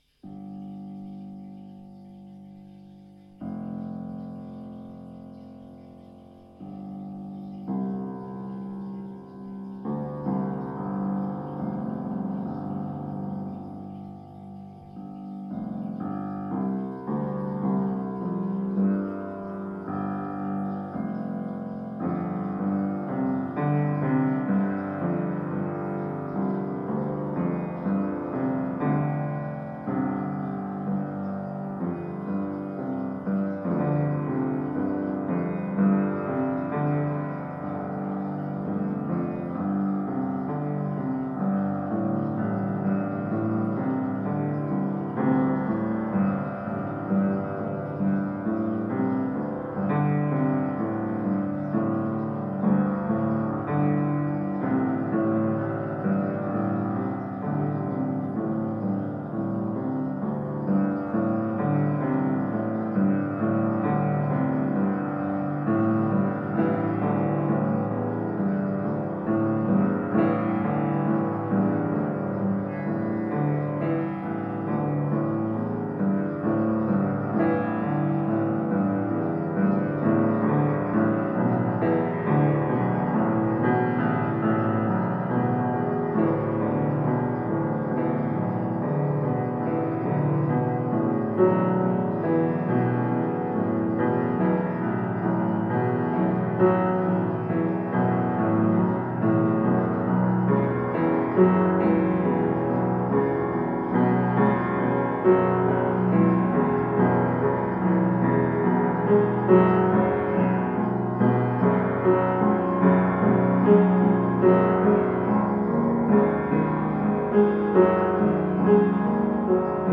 Daher jetzt ein kleiner Praxisteil: Spielt und experimentiert mit zwei, drei und vier benachbarten Quinten.
Quintus IV. mit vier Nachbartönen im Quintenzirkel